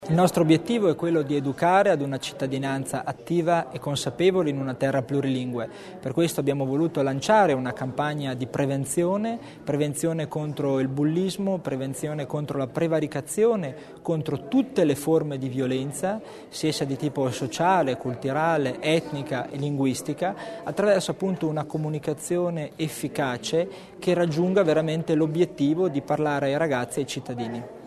Il Vicepresidente Tommasini spiega l'obiettivo del progetto